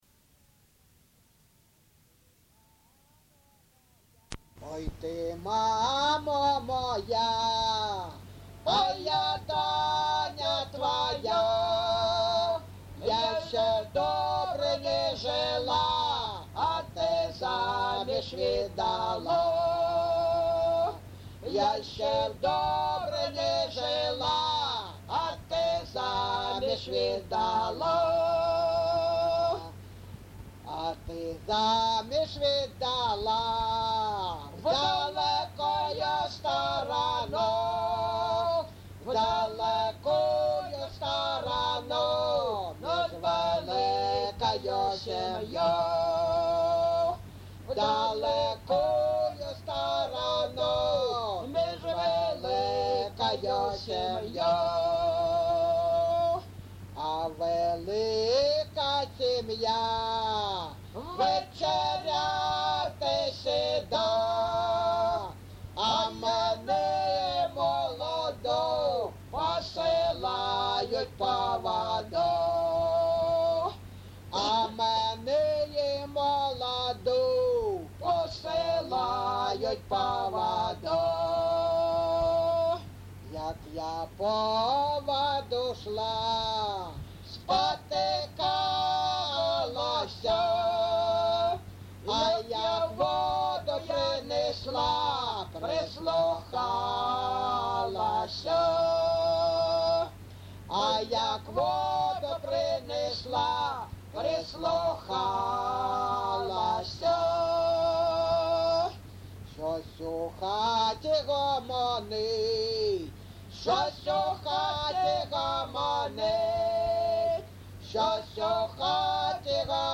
ЖанрПісні з особистого та родинного життя
Місце записус. Григорівка, Артемівський (Бахмутський) район, Донецька обл., Україна, Слобожанщина